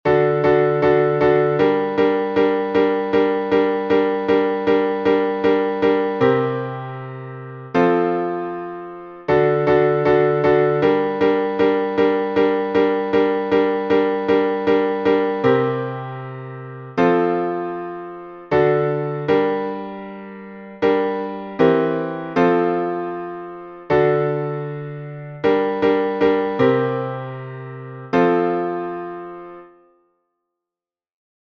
Киевский напев